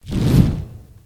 mb-assets/spell-whoosh-1.ogg at acf95b69b28fac2803af3af48e35a7a53e154d59 - mb-assets - Gitea
spell-whoosh-1.ogg